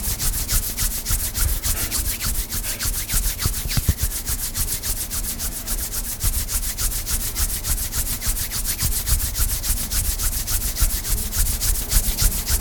공포_손.mp3